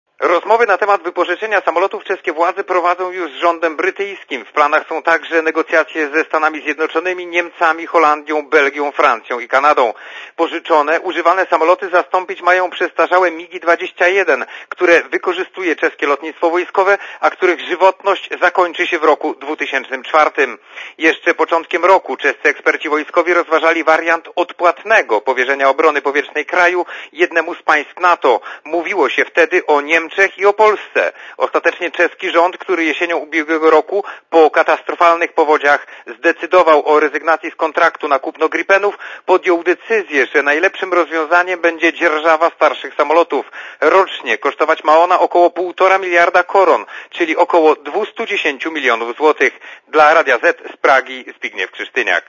Dopiero później nasi południowi sąsiedzi mają zadecydować o kupnie nowych maszyn. Źródło: Archiwum (RadioZet) Źródło: (RadioZet) Korespondencja z Pragi (204) Oceń jakość naszego artykułu: Twoja opinia pozwala nam tworzyć lepsze treści.